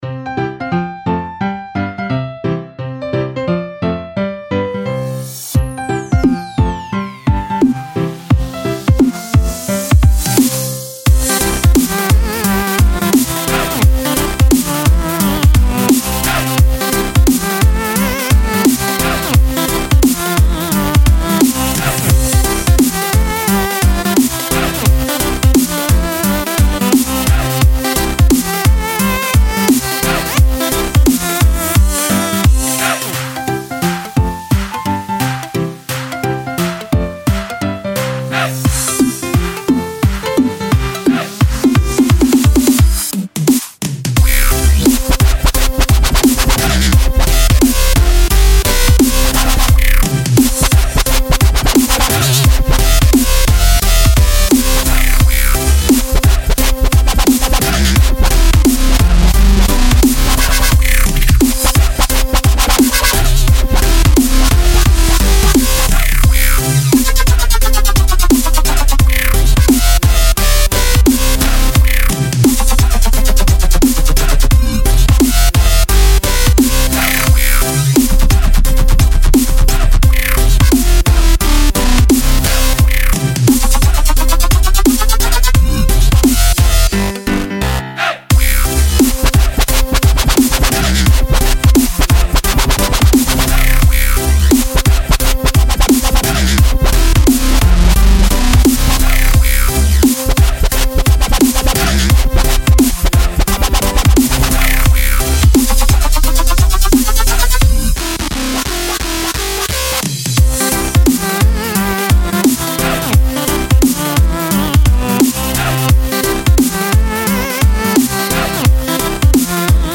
Žánr: Electro/Dance
Genres: Dubstep, Music, Electronic, Dance